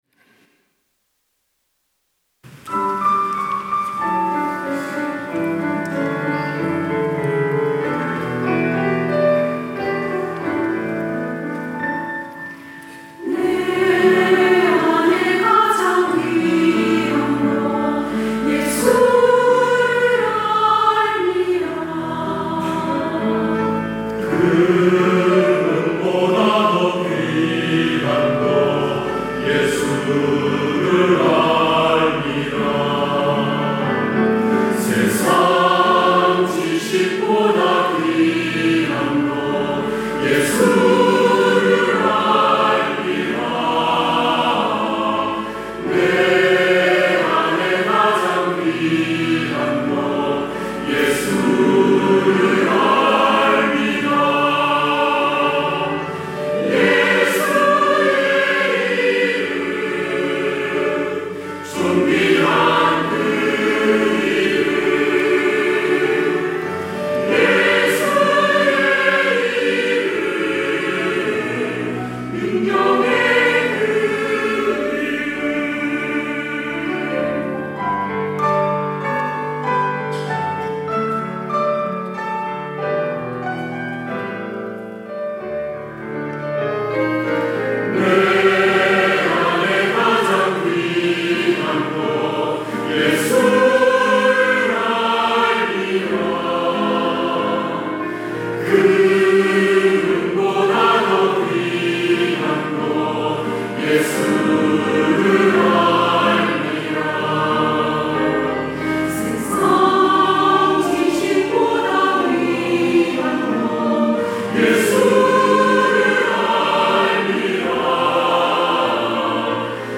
할렐루야(주일2부) - 내 안에 가장 귀한 것
찬양대